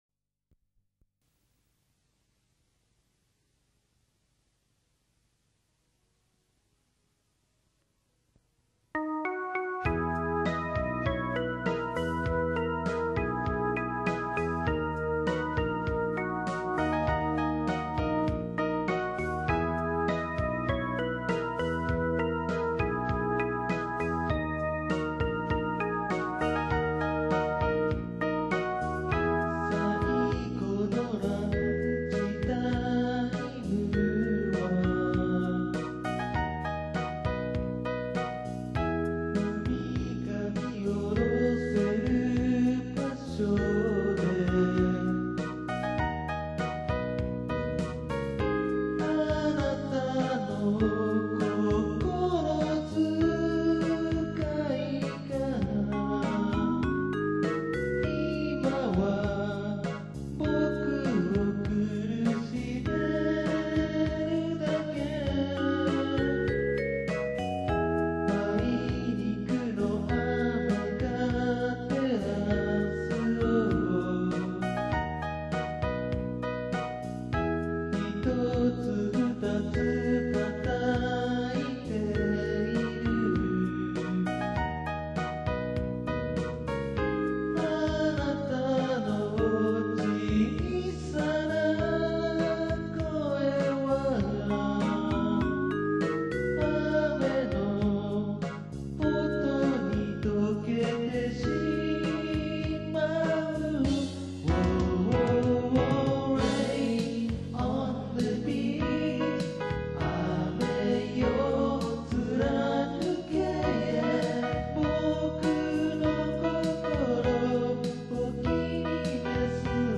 雨音をあしらったアレンジは我ながら気に入っている。